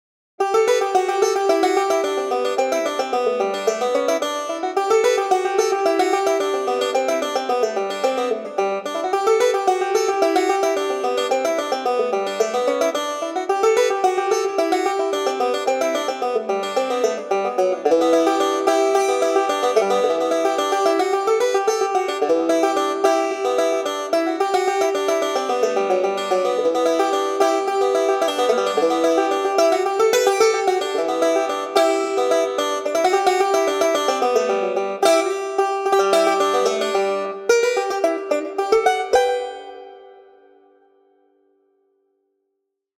Picked bluegrass banjo for Kontakt